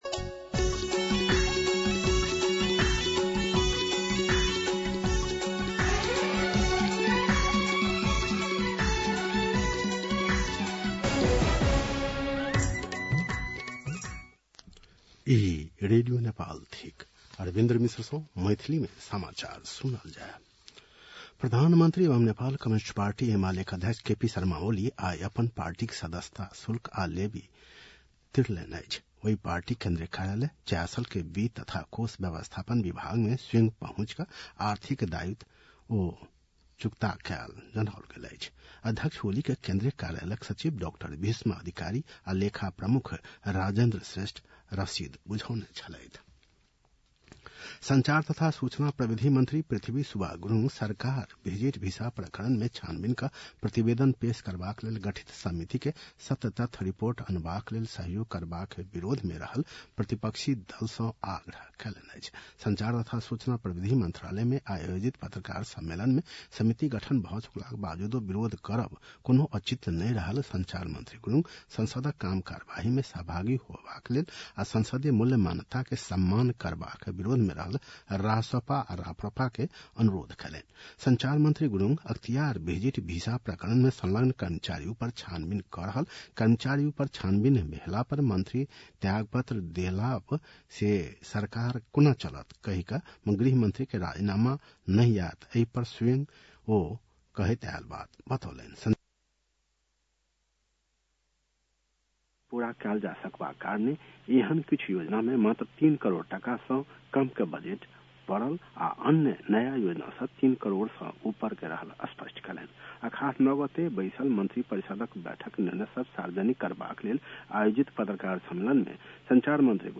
An online outlet of Nepal's national radio broadcaster
मैथिली भाषामा समाचार : ११ असार , २०८२